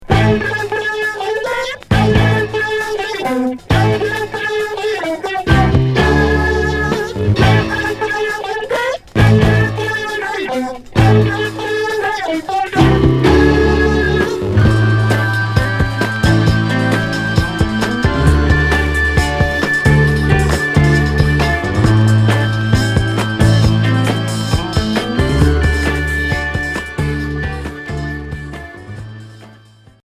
Psyché pop